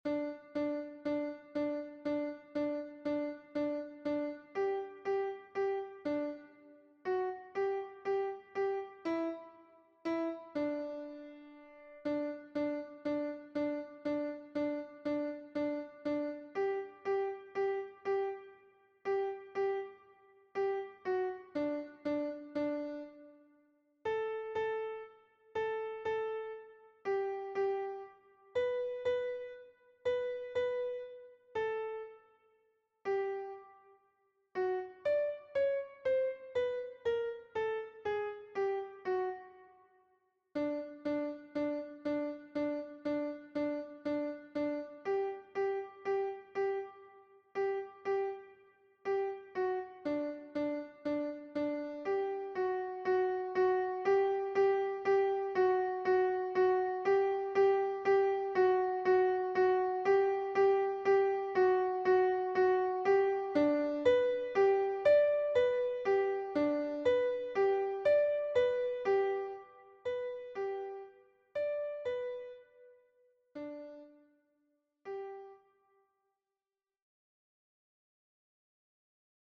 MP3 version piano
Alto 1